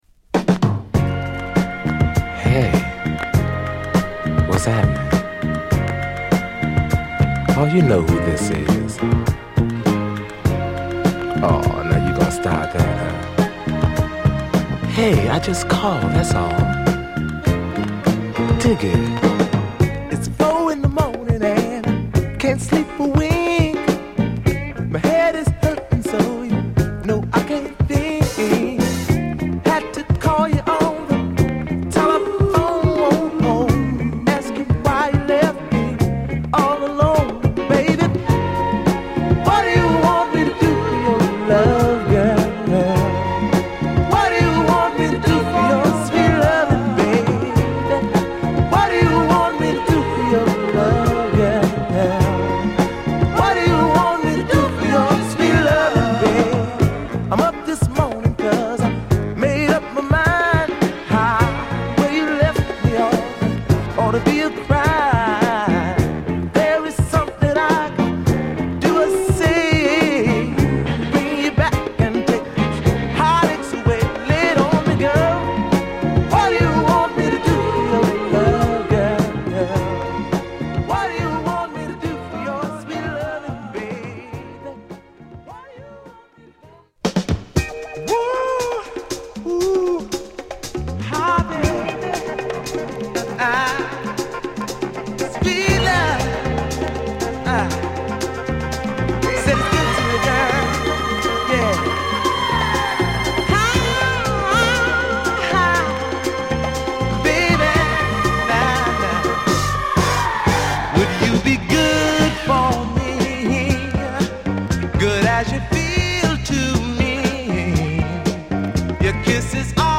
ヌケの良いドラムと爽やかなフルート/ストリングスが心地良い、グルーヴィーで伸びやかなグッド・メロウ・ソウル！
*イントロ数回転ノイズ有。